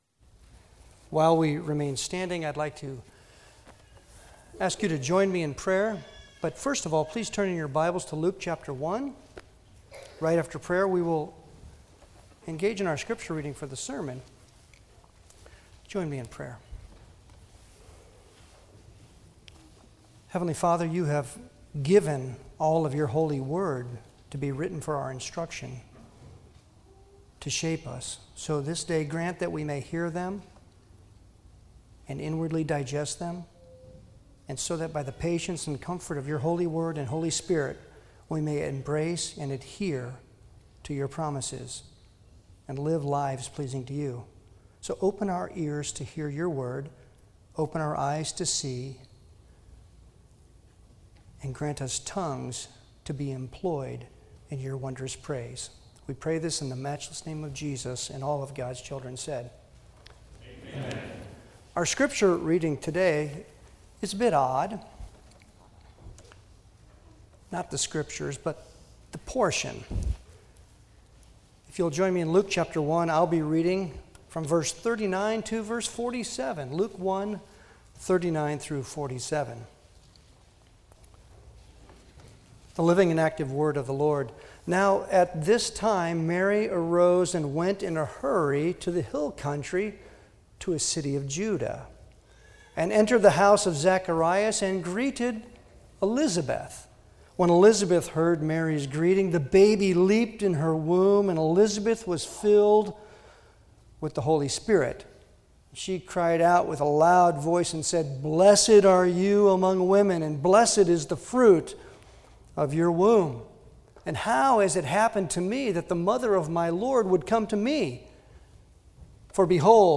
Sermons on the Gospel of Luke Passage: Luke 1:39-47 Service Type: Sunday worship Download Files Bulletin « Day One